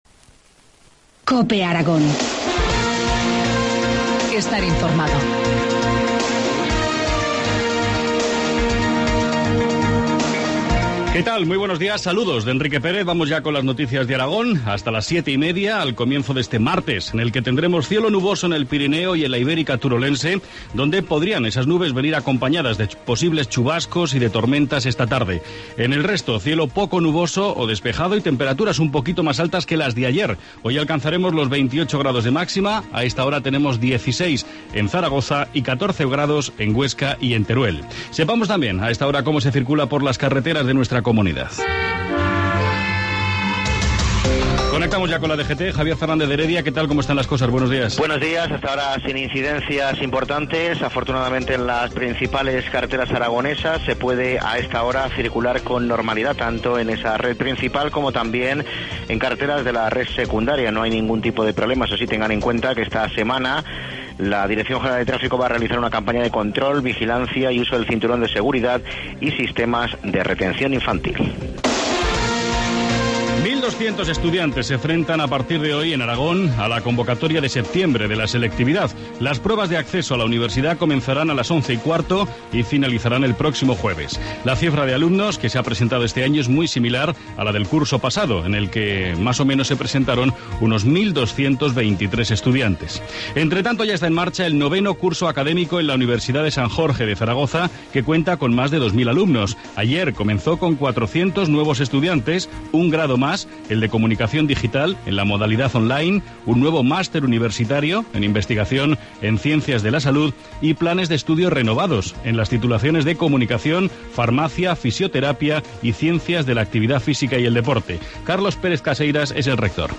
Informativo matinal, martes 9 septiembre, 2013, 7,25 horas